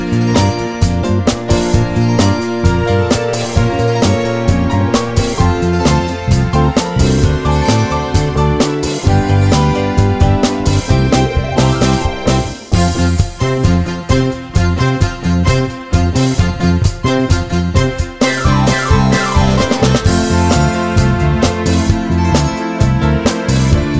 Without Bass Guitar Disco 3:18 Buy £1.50